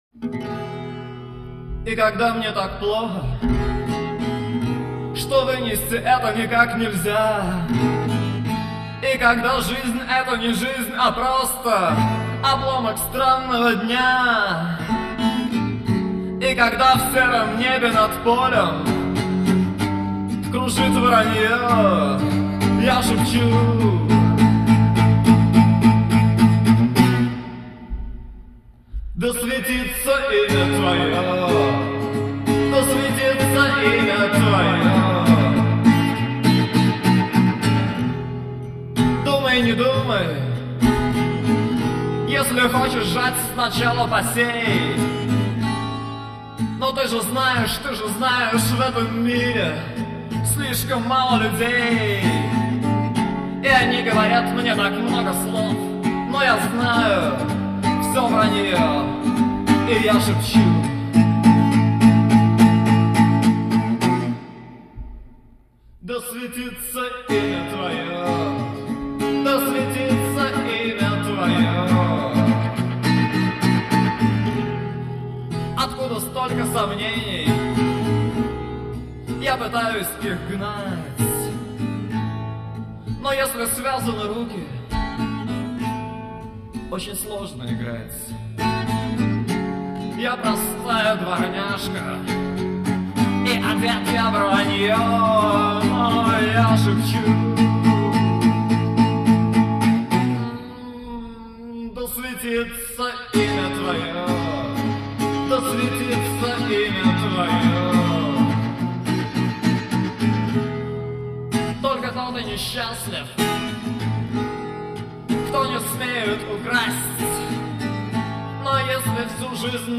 губная гармошка, голос.